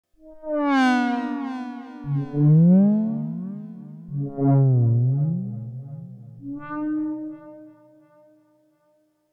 WhaleTalk 1.wav